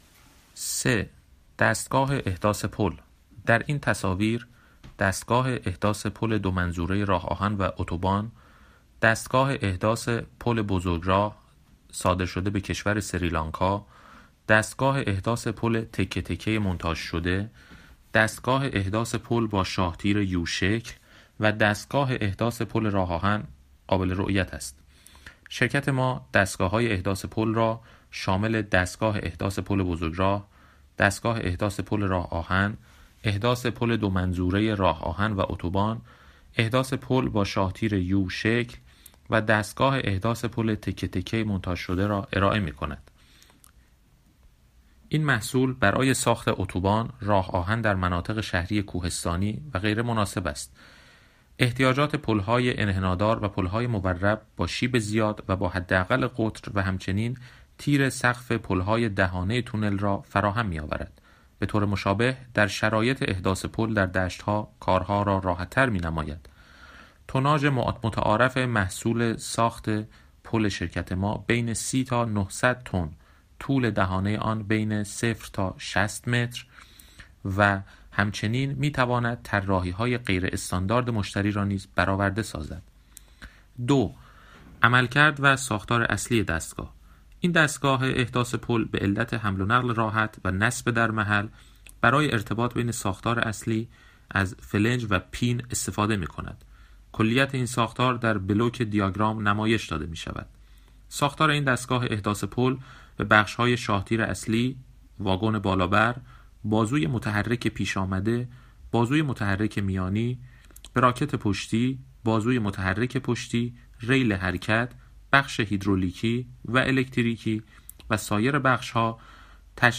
企业宣传【厚重大气】